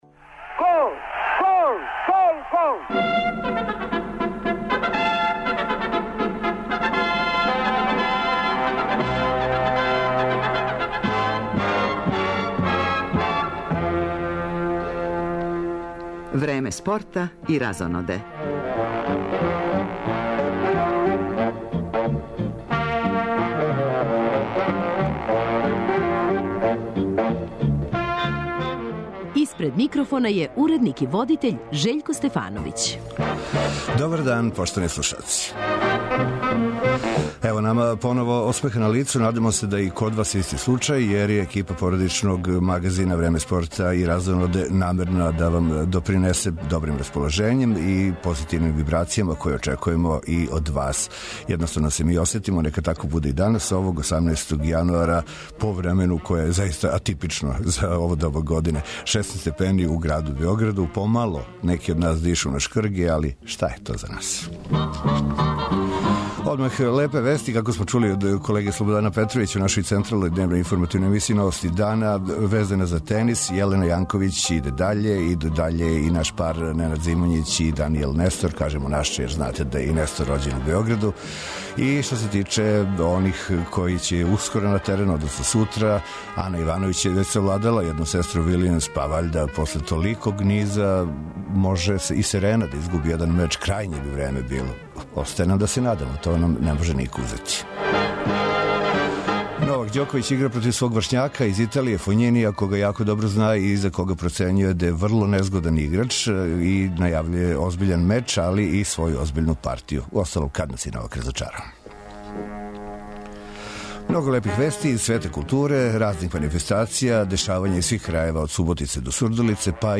Поводом премијере филма "Кад љубав закасни", гост у студију нам је глумица Бранкица Себастијановић.